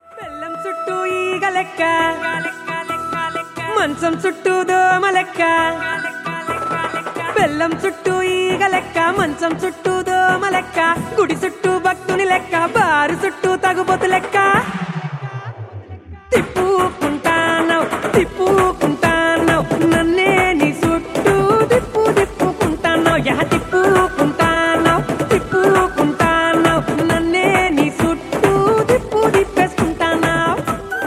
energetic and trending
loud, clear
best flute ringtone download
dance ringtone party ringtone